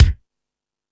Lazer Drums(02).wav